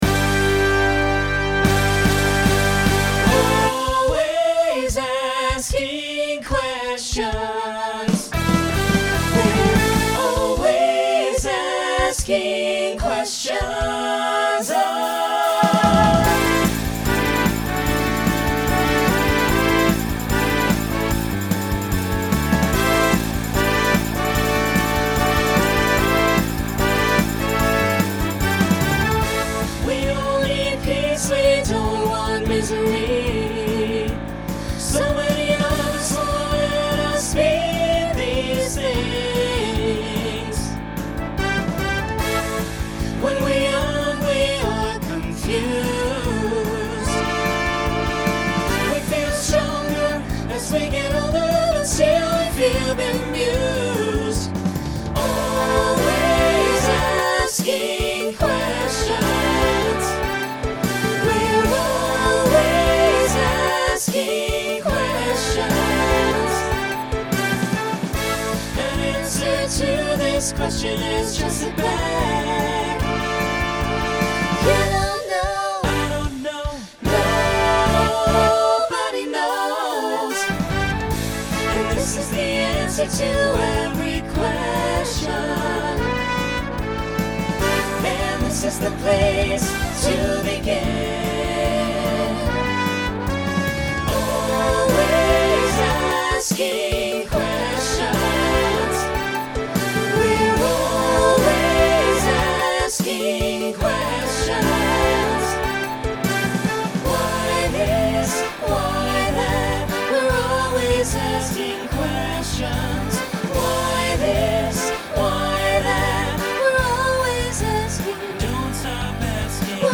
Genre Rock Instrumental combo
Voicing SAB